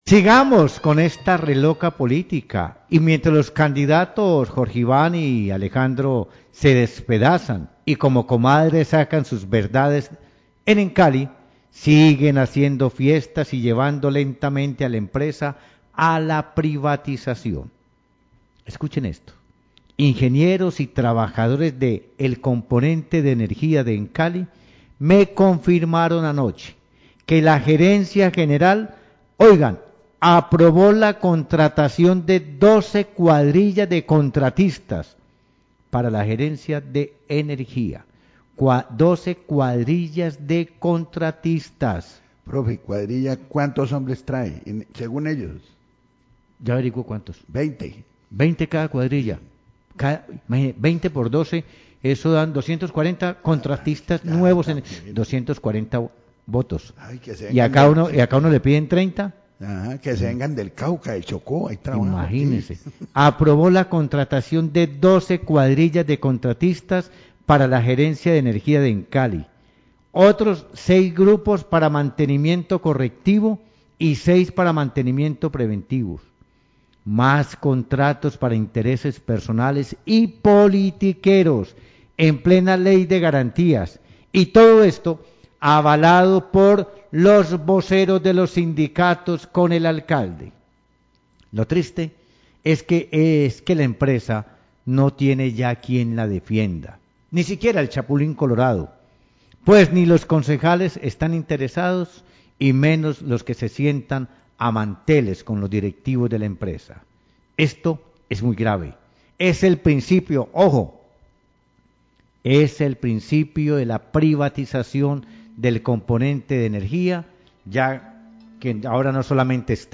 Radio
Senador Alexander López habla sobre la aprobación para contratar 12 cuadrillas para la gerencia de energía. Señala que la tercerización está poniendo en riesgo las labores operativas de los componentes de Emcali.